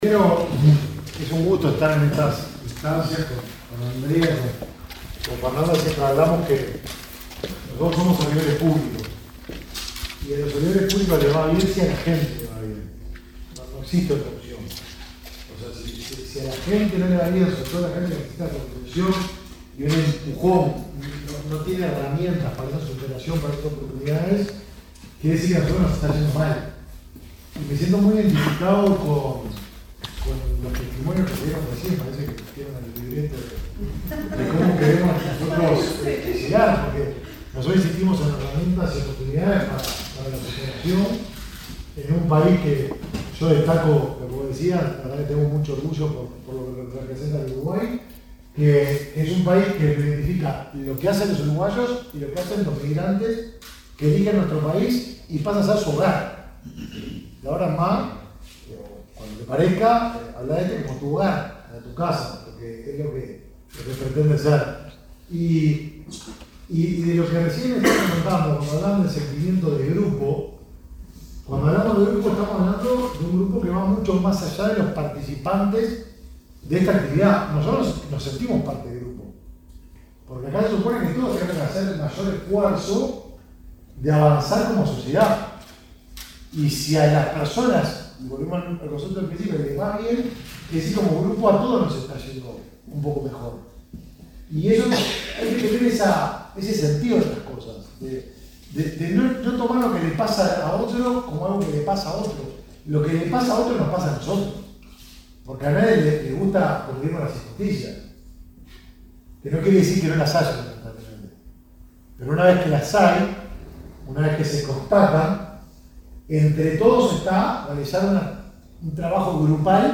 Palabras del ministro de Desarrollo Social, Martín Lema
Este viernes 22, en Montevideo, el ministro de Desarrollo Social, Martín Lema, participó en la presentación del proyecto Avanza, cuya finalidad es